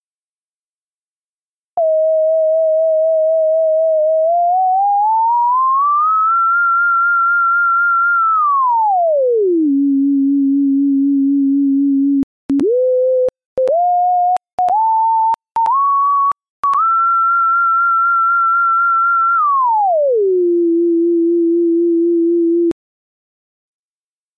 Deberemos prestar especial atención ós cambios de altura que fai o son, que crearán as curvas e a dirección da nosa liña.